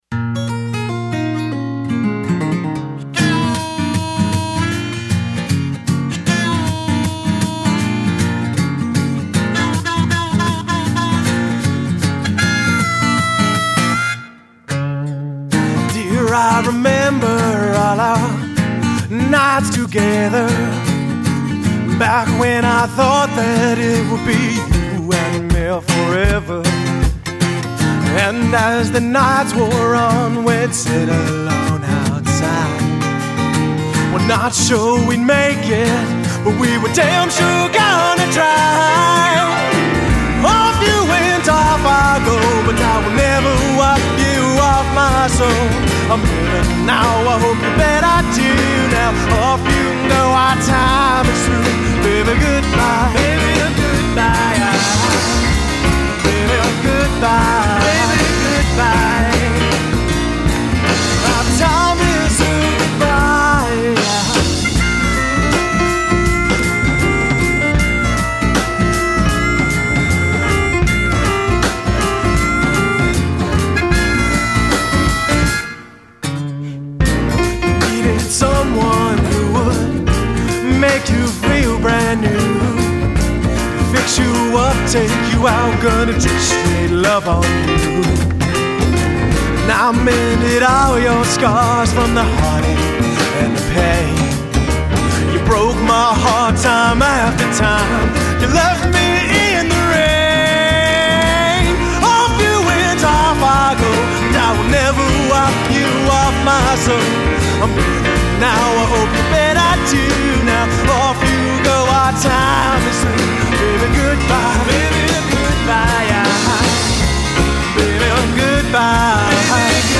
Guitar, Harmonica, Vocals
Drums
Piano
Upright Bass